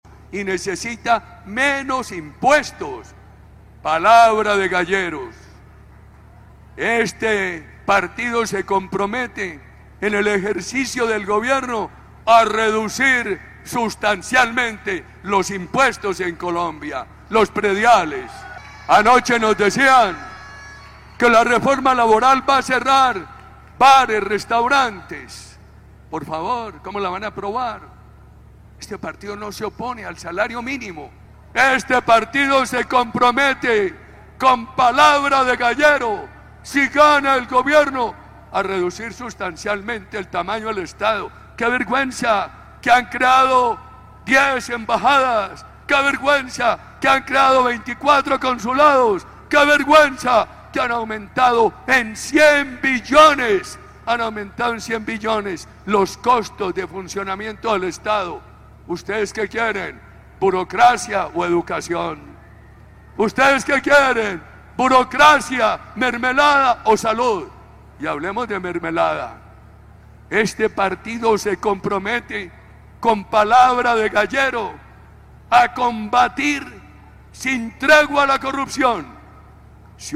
Así se comprometió el expresidente Álvaro Uribe Vélez durante foro con precandidatos presidenciales del Centro Democrático en Armenia
En ese escenario liderado por el expresidente Álvaro Uribe Vélez toma la palabra en dos momentos frente a los asistentes, al inicio y al final del foro, donde habló de temas de educación, seguridad, reforma a la salud, burocracia y el tema de impuestos.